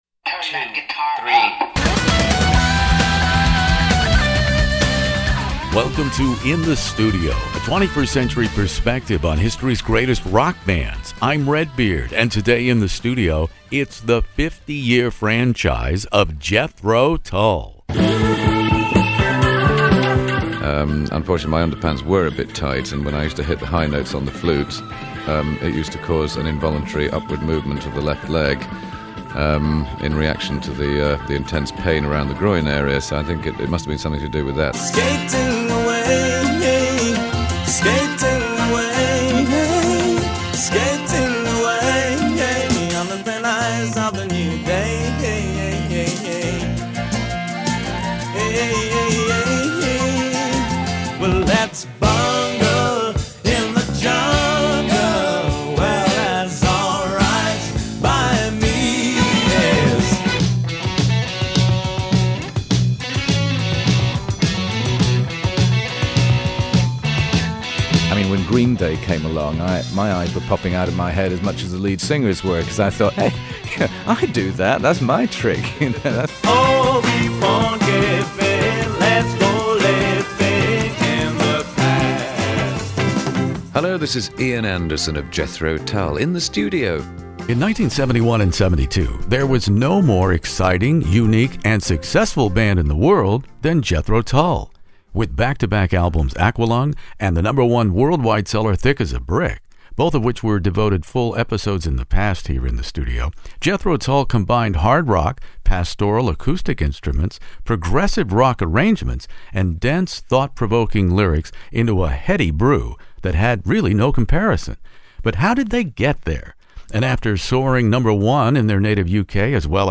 Jethro Tull's Ian Anderson "In the Studio" interview "War Child"